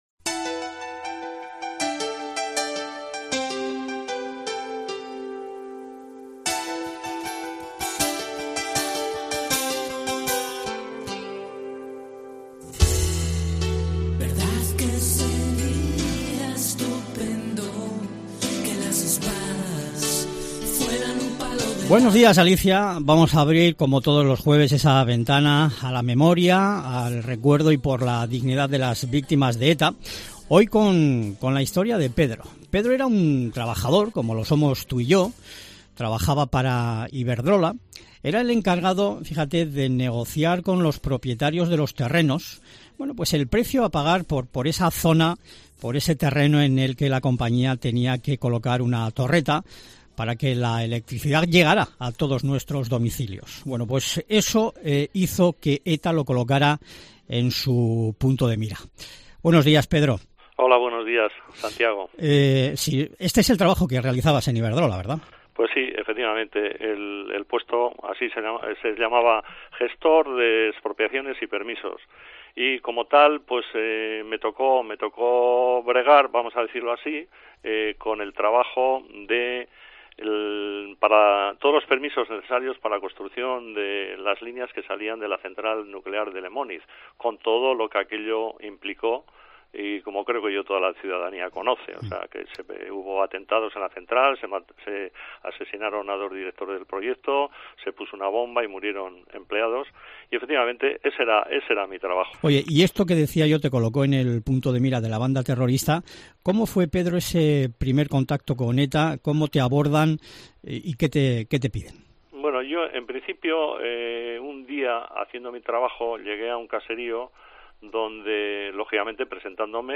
Hoy en COPE Euskadi hemos entrevistado a una de las víctimas de extorsión que han vivido la dura experiencia de verse amenazadas si no pagaban el llamado "impuesto revolucionario" durante los años del terrorismo de ETA.